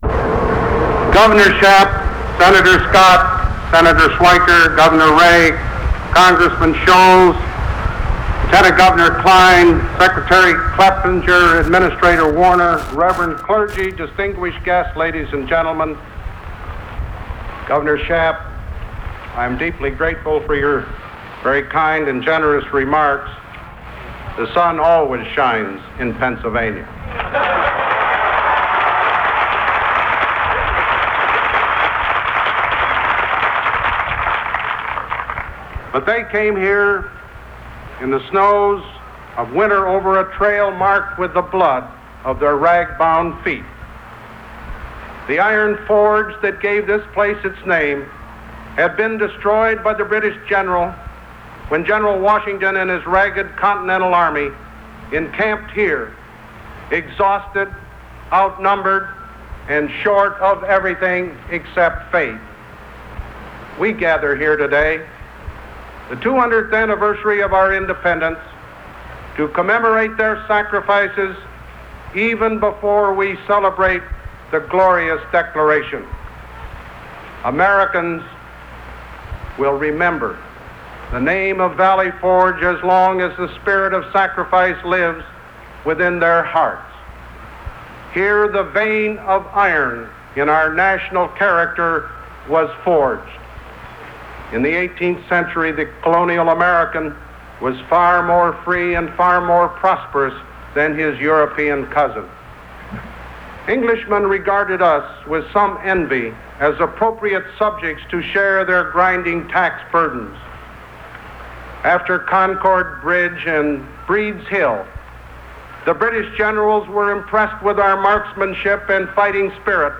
President Ford speaks at Valley Forge, Pennsylvania, and declares the site of the famous Revolutionary War encampment a national park
Broadcast on NBC TV, July 4, 1976.